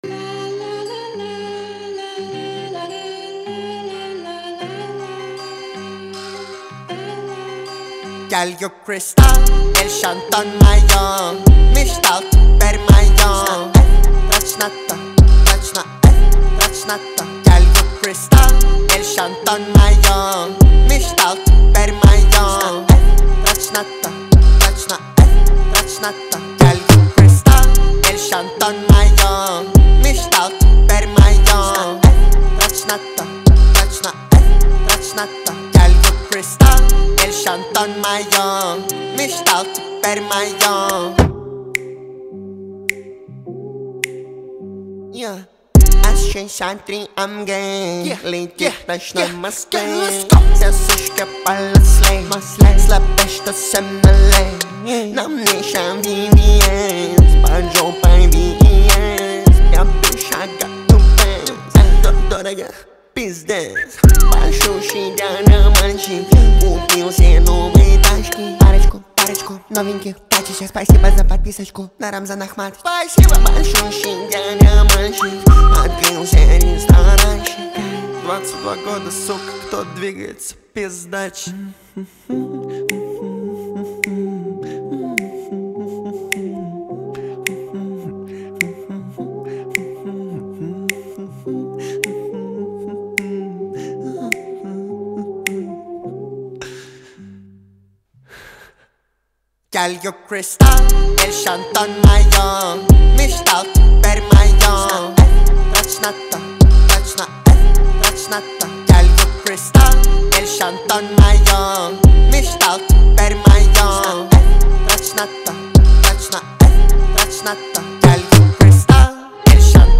اهنگ مافیایی معروف